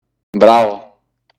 Descarga de Sonidos mp3 Gratis: bravo 1.
bravo.mp3